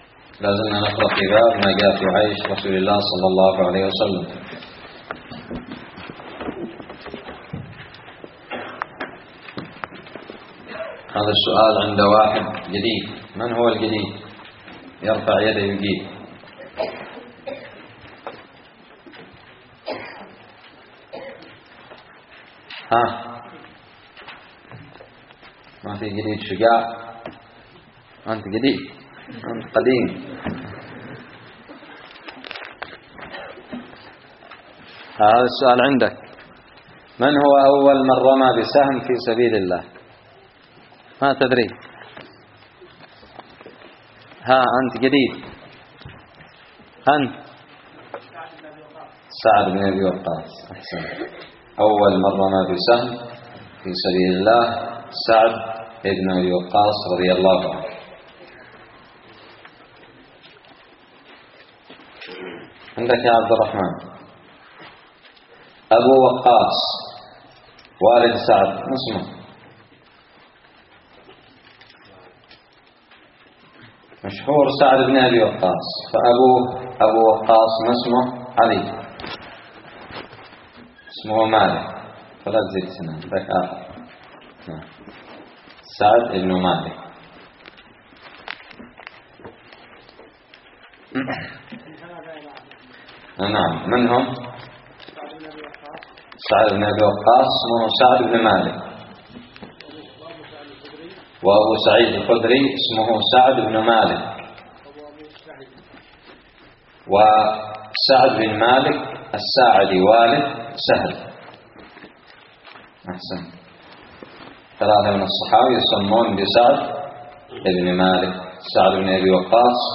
الدرس الرابع والعشرون بعد المائة من شرح كتاب الشمائل المحمدية
ألقيت بدار الحديث السلفية للعلوم الشرعية بالضالع